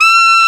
SAX A.MF F0O.wav